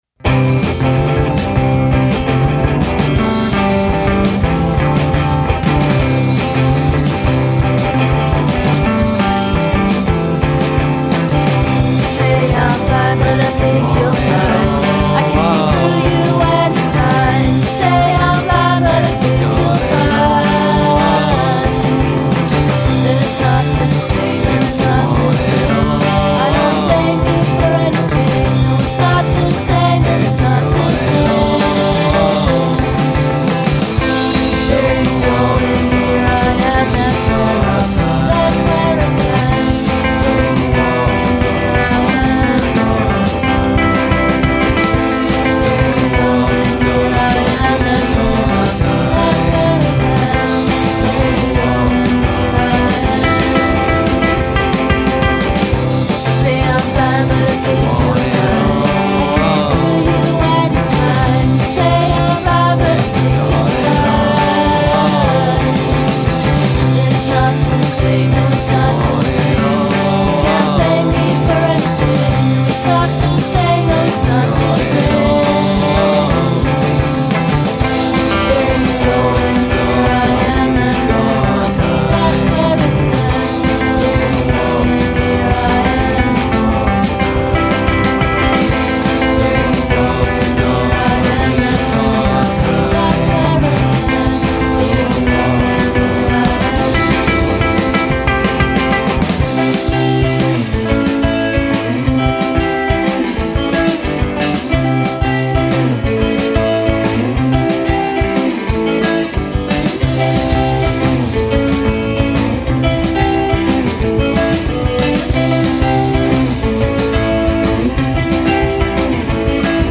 in cui tutte le voci si intrecciano